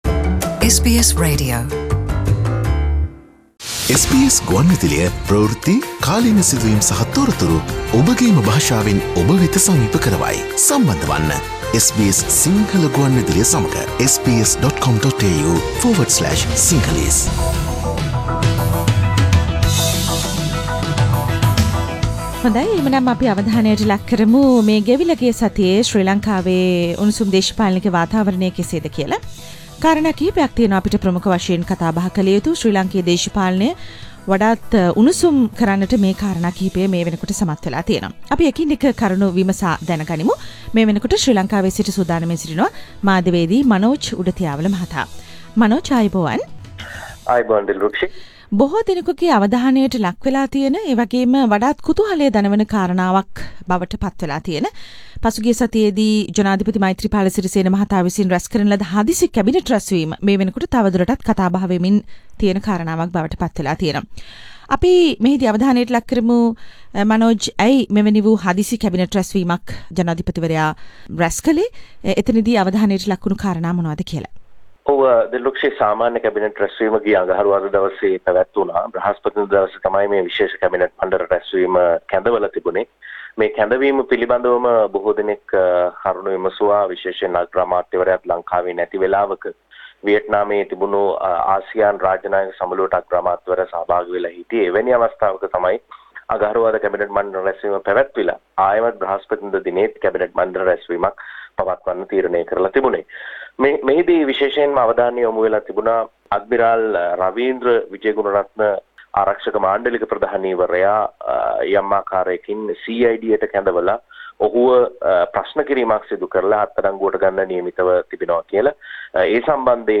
පුවත් සමාලෝචනය